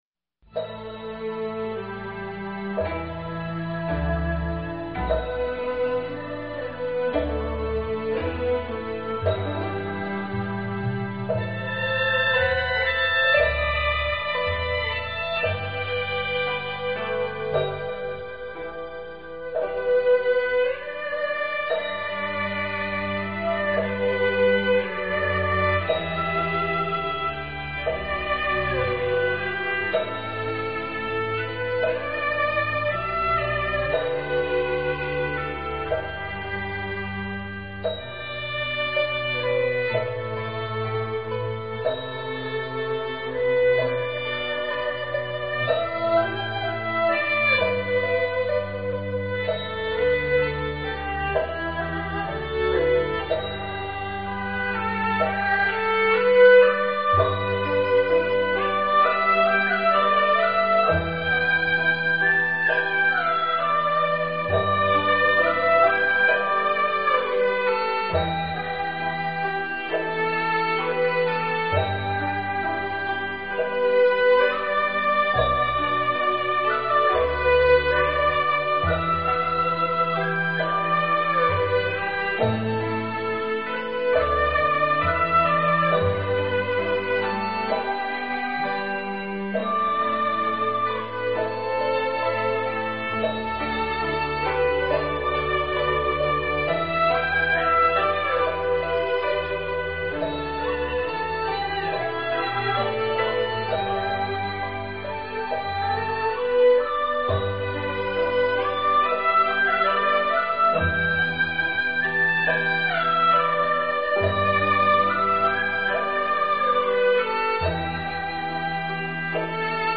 佛音 冥想 佛教音乐 返回列表 上一篇： 看破(古琴